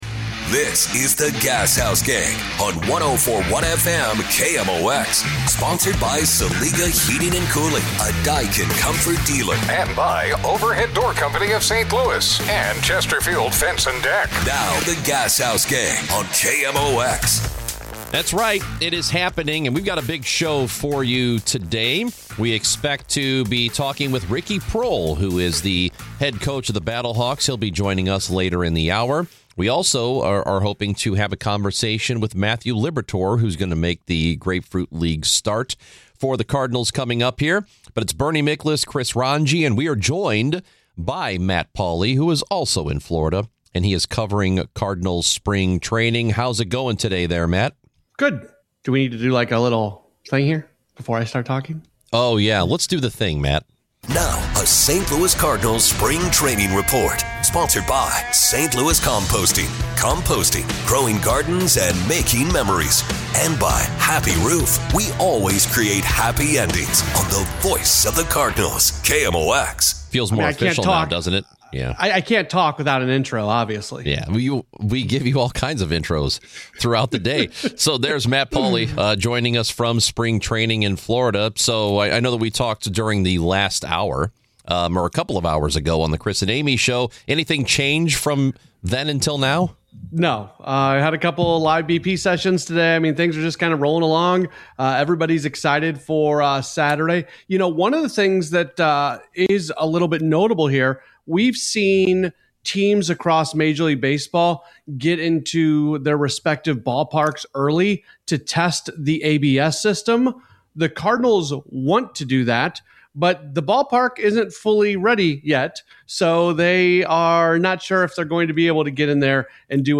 Ricky Proehl, St. Louis Battlehawks head coach and former Rams wide receiver, joins in-studio to preview the upcoming 2026 season which will be his first as top dog.